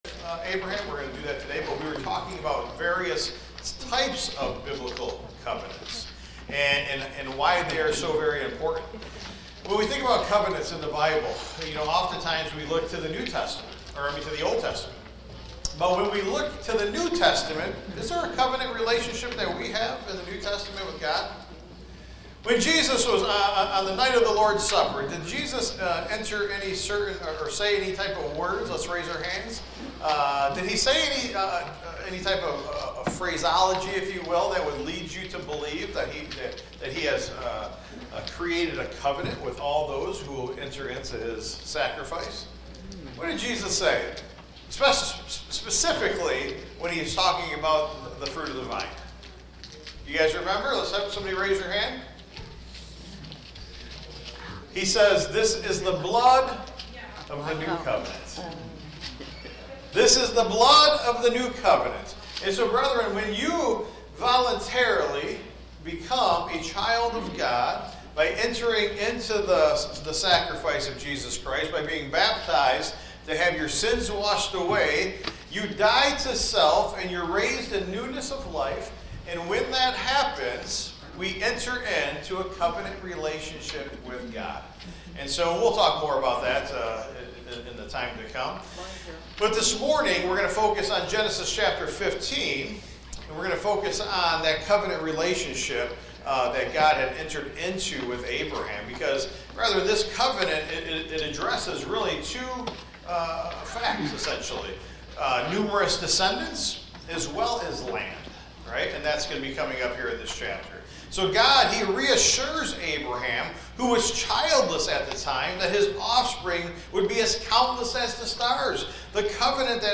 Taught live Sunday